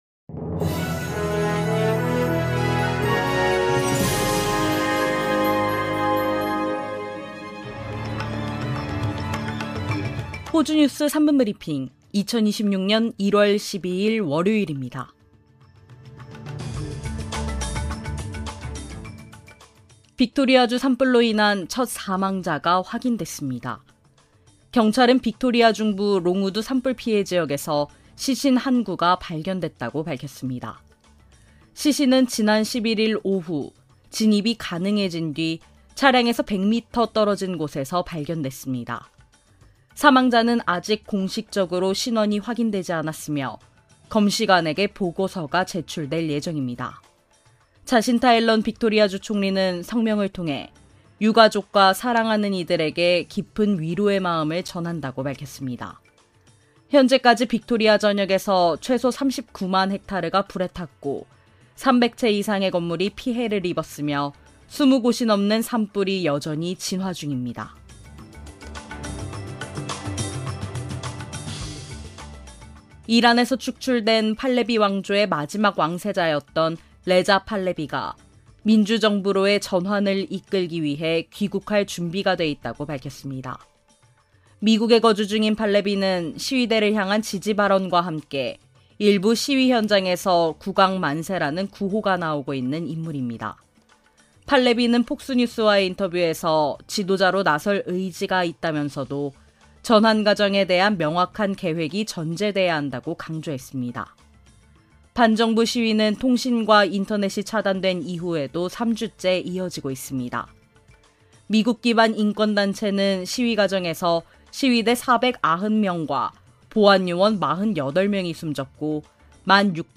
호주 뉴스 3분 브리핑: 2026년 1월 12일 월요일